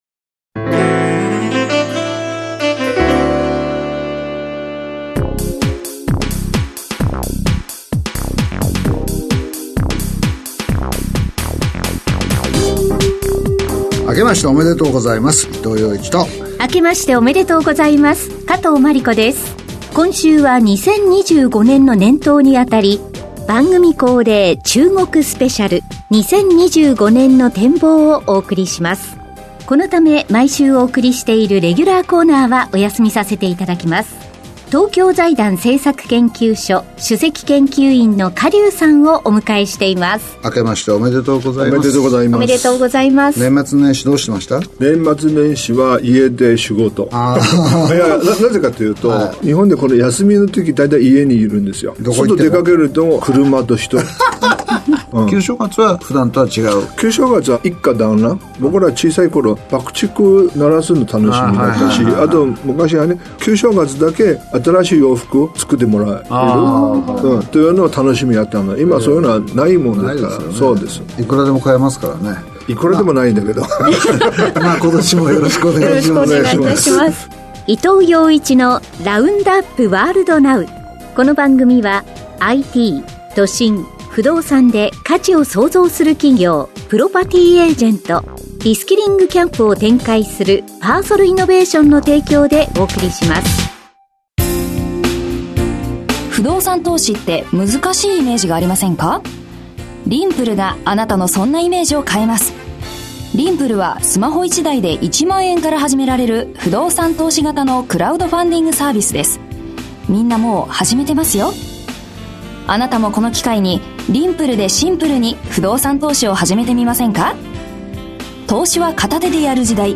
… continue reading 468 odcinków # ニューストーク # ニュース # ビジネスニュース # NIKKEI RADIO BROADCASTING CORPORATION